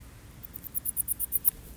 If you do not know what the calls a possum joey makes to its mother sounds like – please click the buttons below to hear the specific species audio recordings.
Ringtail Calling
RT-Call.m4a